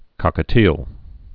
(kŏkə-tēl)